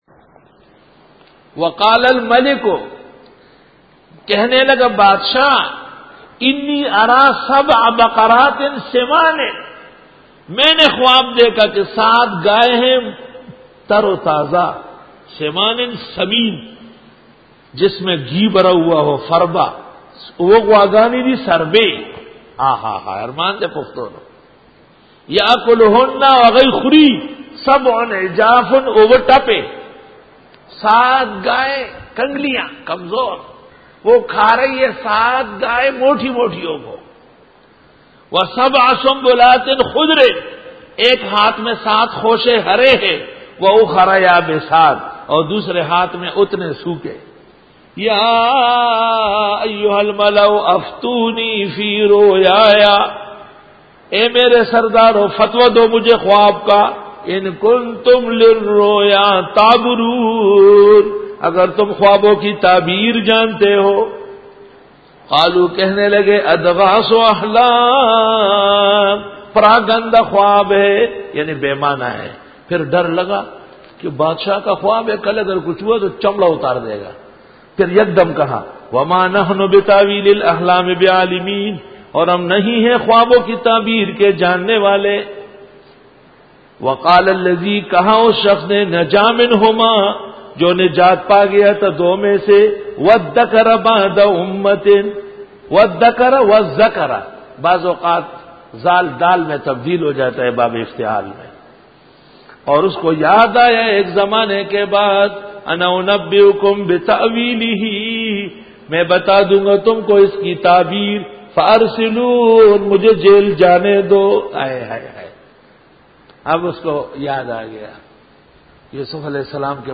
سورۃ یوسف رکوع-06 Bayan